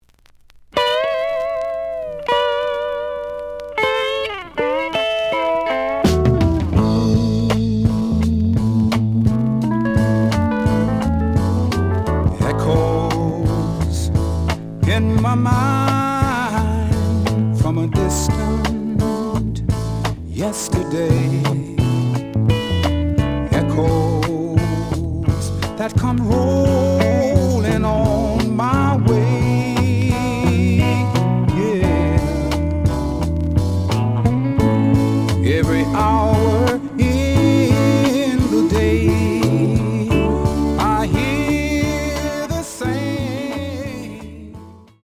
The audio sample is recorded from the actual item.
●Format: 7 inch
●Genre: Soul, 60's Soul
Slight edge warp.